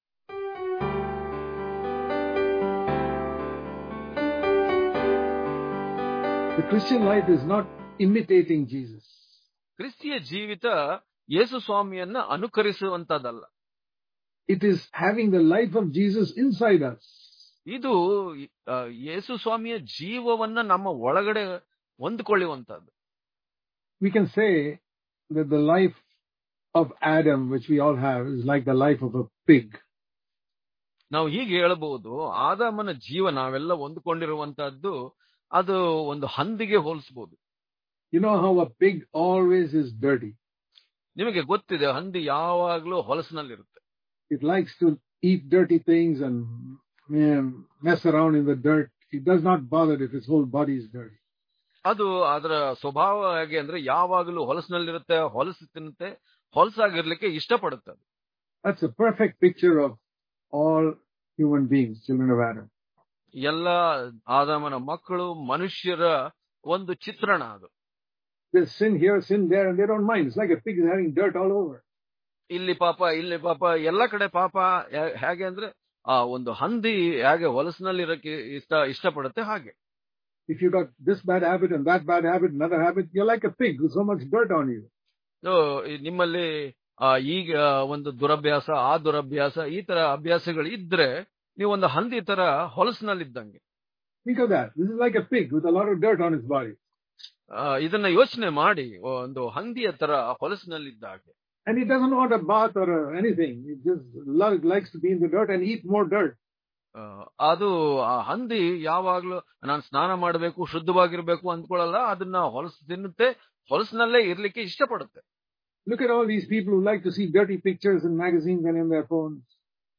July 14 | Kannada Daily Devotion | The Nature Of Adam And The Nature Of God Daily Devotions